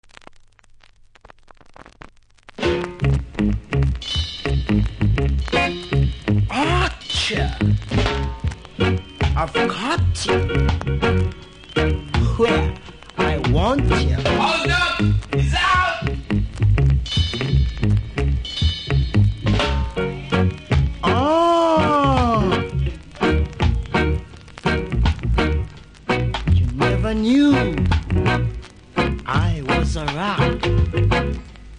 Vocals
盤の見た目はキズ多めで良くないですがそこそこ聴けます。